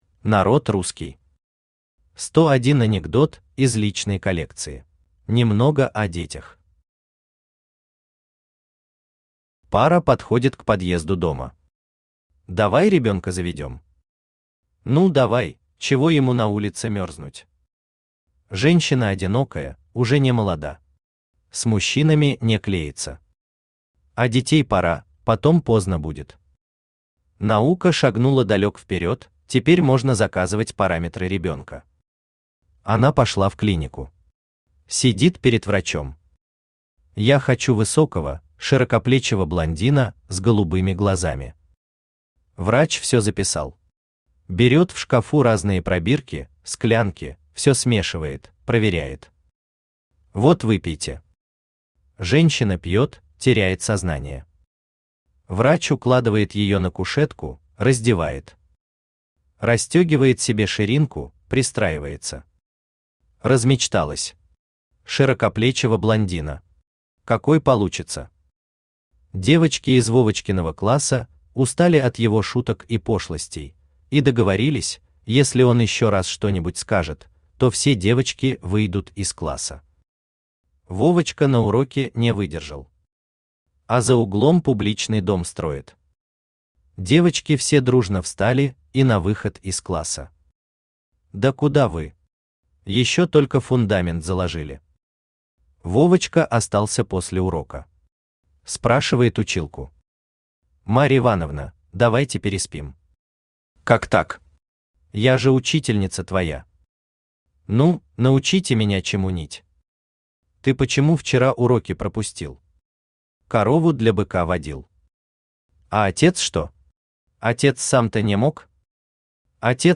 Аудиокнига 101 анекдот из личной коллекции | Библиотека аудиокниг
Aудиокнига 101 анекдот из личной коллекции Автор Народ Русский Читает аудиокнигу Авточтец ЛитРес.